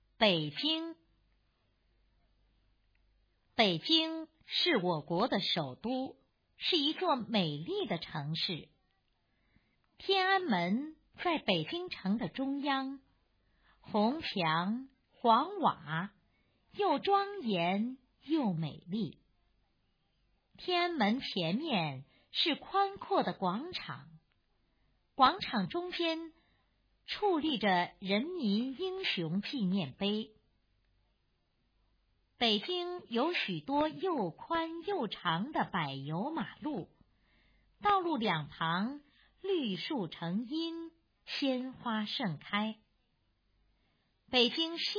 北京 课文朗读